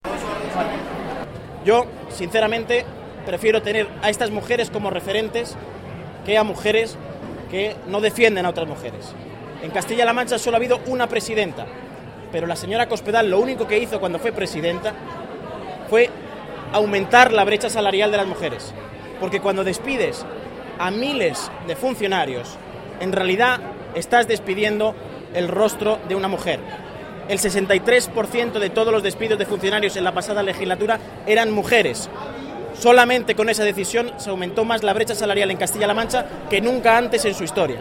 El acto de entrega de los ‘Rosa Roja’ ha tenido lugar en el  Museo del Vino de Valdepeñas
Cortes de audio de la rueda de prensa